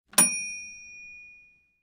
Microwave Oven
Microwave_Oven.mp3